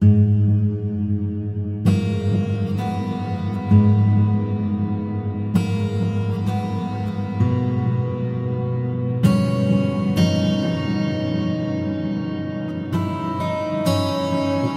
描述：原声吉他循环
Tag: 130 bpm Ambient Loops Guitar Acoustic Loops 2.48 MB wav Key : G